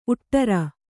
♪ uṭṭara